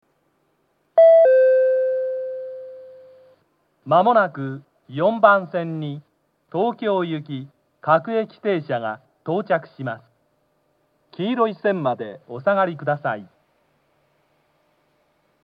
４番線接近放送 各駅停車東京行の放送です。
kaihimmakuhari-4bannsenn-sekkinn.mp3